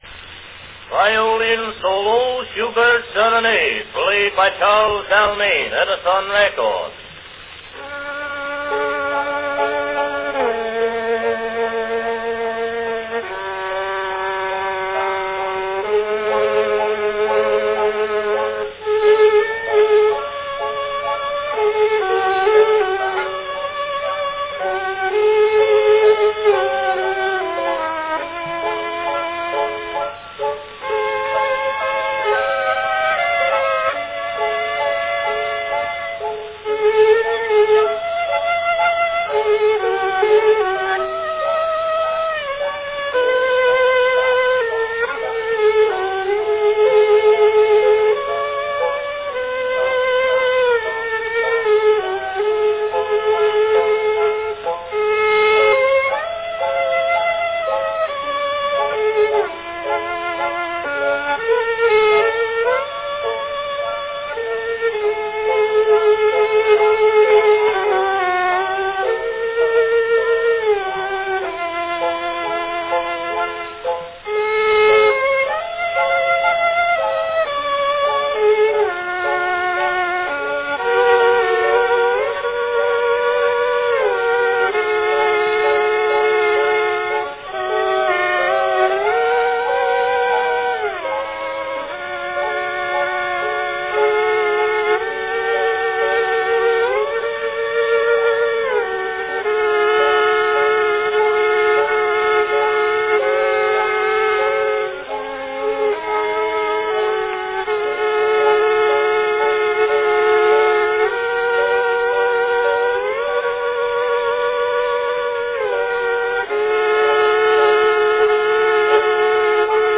a fine violin recording
Category Violin solo
This particular recording is likely a 1908 re-make of Edison's original 1899 brown wax arrangement.